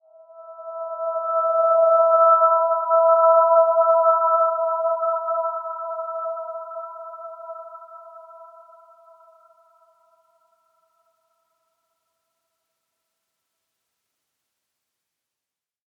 Dreamy-Fifths-E5-p.wav